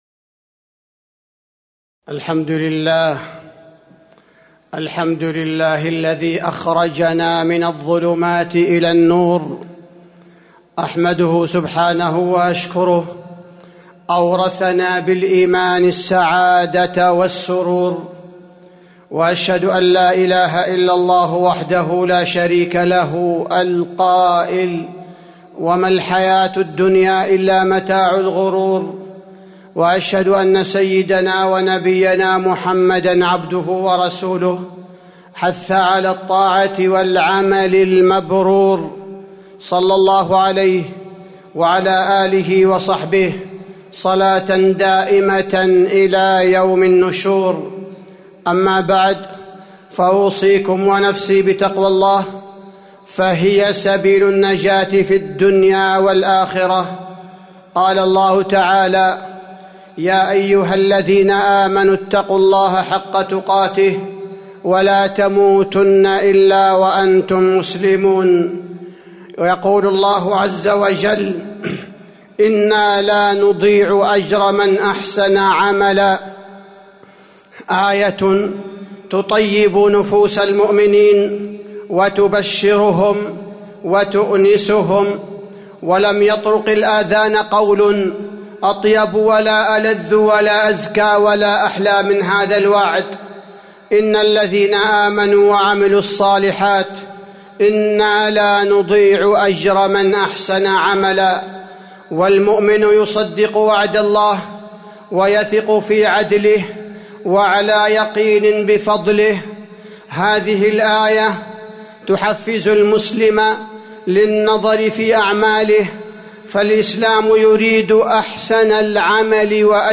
تاريخ النشر ١٧ رجب ١٤٤٣ هـ المكان: المسجد النبوي الشيخ: فضيلة الشيخ عبدالباري الثبيتي فضيلة الشيخ عبدالباري الثبيتي إنا لانضيع أجر من أحسن عملاً